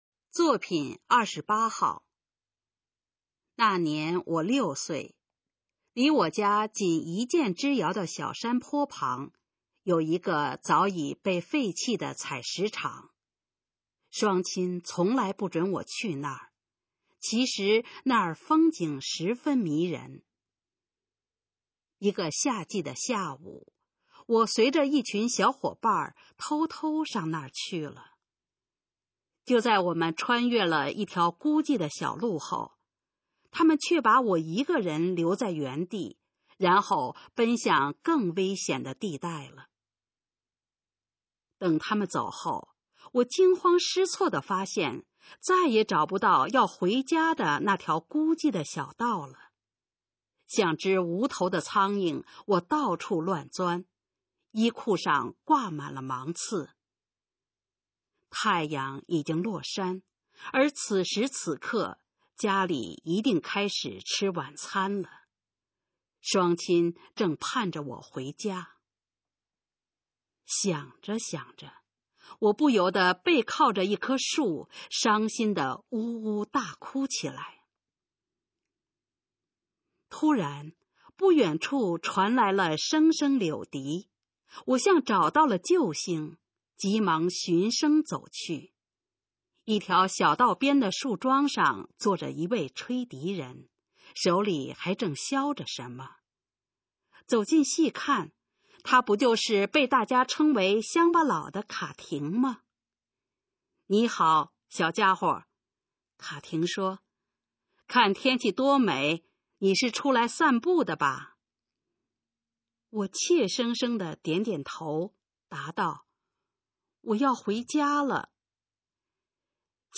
首页 视听 学说普通话 作品朗读（新大纲）
《迷途笛音》示范朗读_水平测试（等级考试）用60篇朗读作品范读